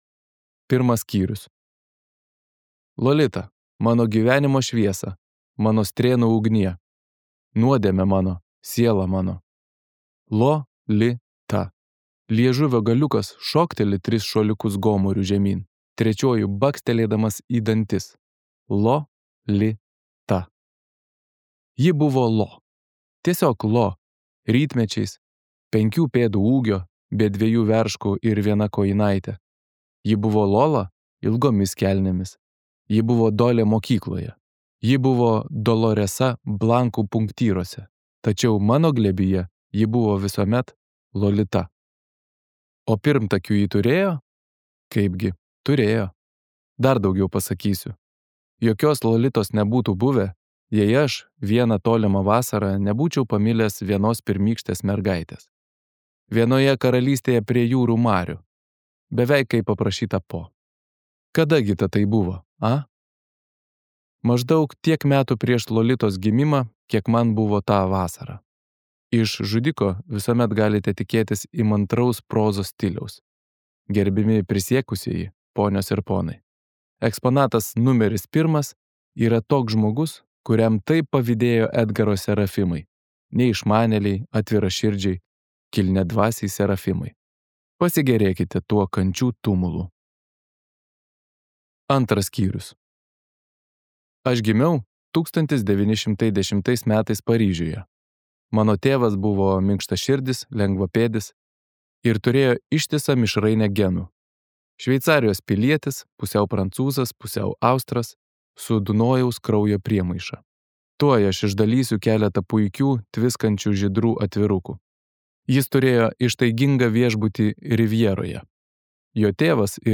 Lolita | Audioknygos | baltos lankos